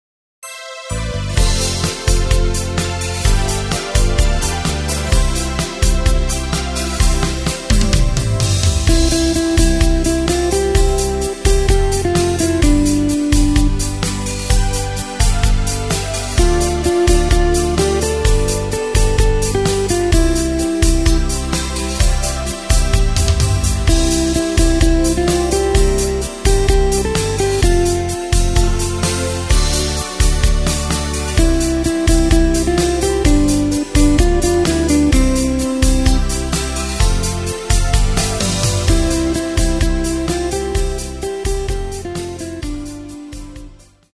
Takt:          4/4
Tempo:         128.00
Tonart:            C
Schöner Schlager!